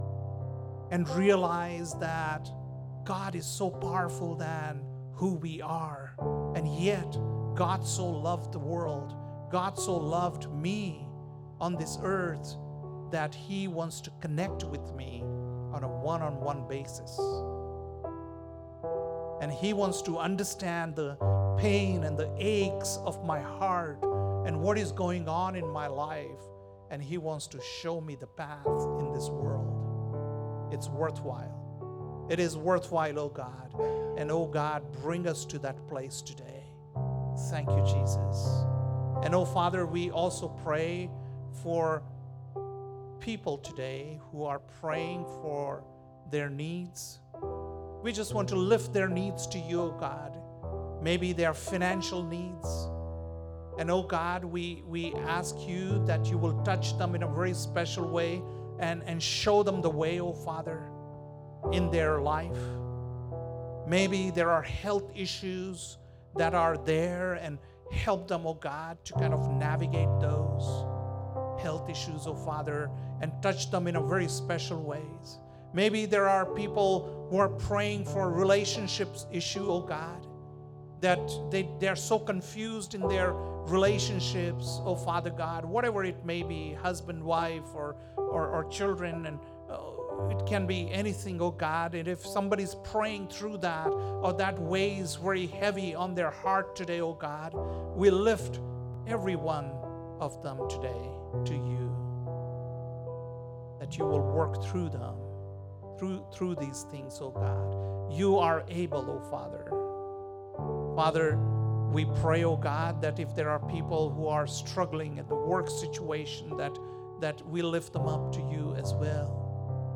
November 10th, 2024 - Sunday Service - Wasilla Lake Church